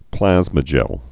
(plăzmə-jĕl)